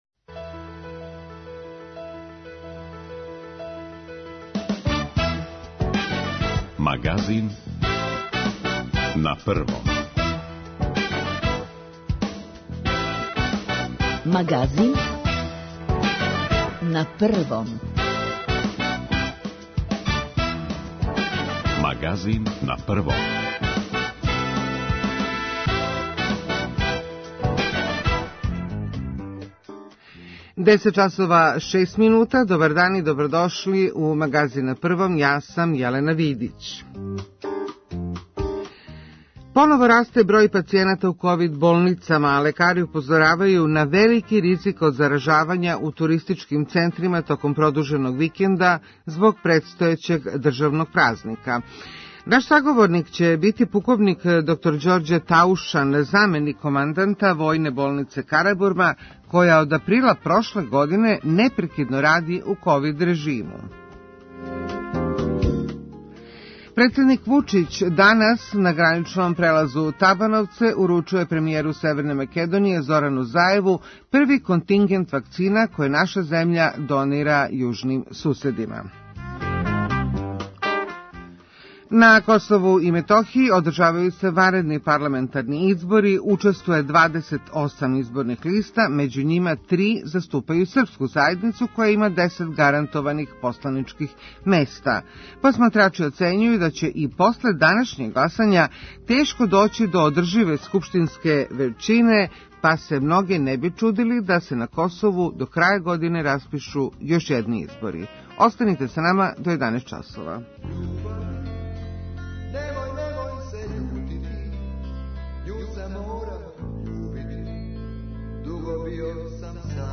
[ детаљније ] Све епизоде серијала Аудио подкаст Радио Београд 1 Европска платформа за инклузију Рома Положај Рома у Европи 57.